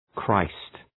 Προφορά
{kraıst}